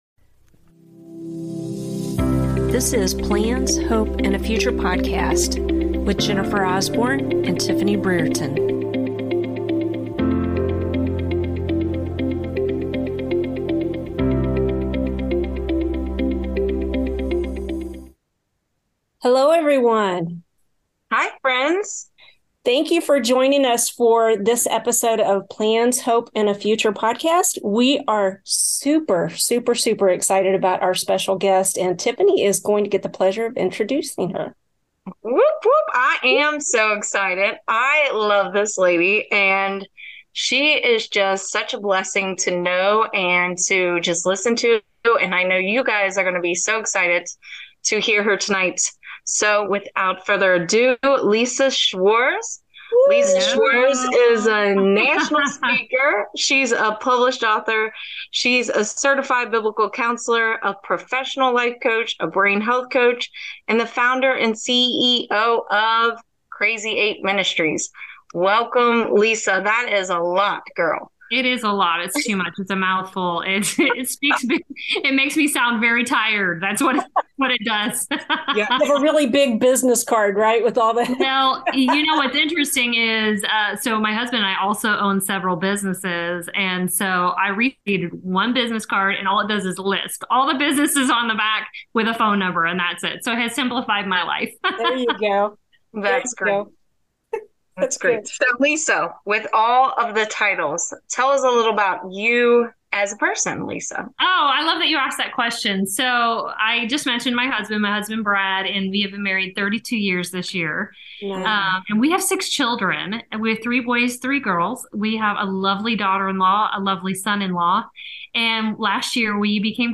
Please stay with it, it only happens at the beginning.